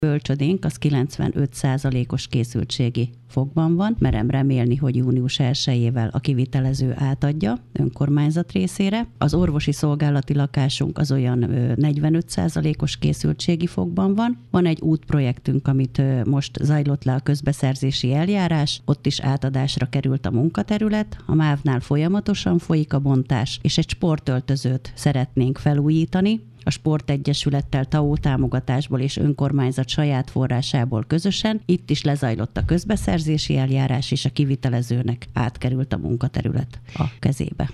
Hírek
Nagy Andrásné polgármester sorolta fel, milyen készültségi szinten vannak az idei beruházások.